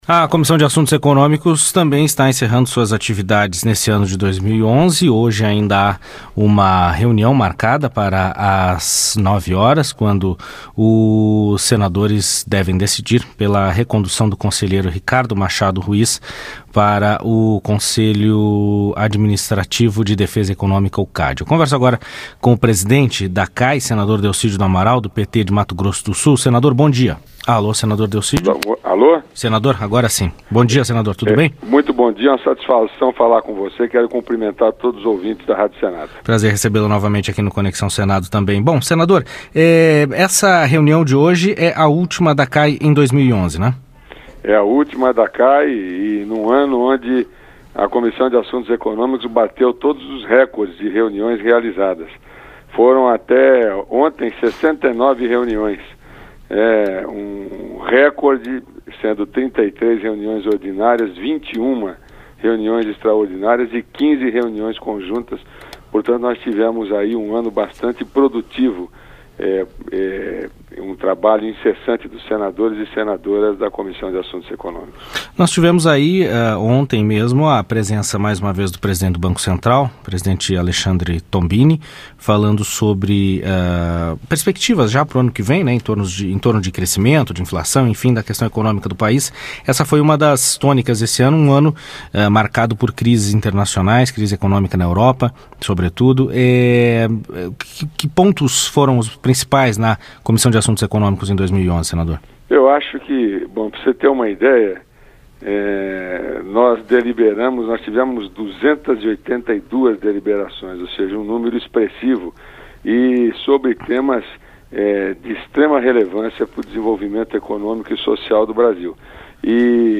Entrevista com o senador Delcídio do Amaral (PT-MS), presidente da Comissão de Assuntos Econômicos.